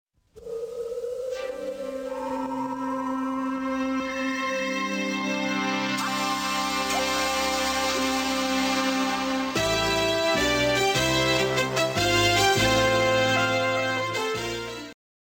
• Качество: 128, Stereo
заставка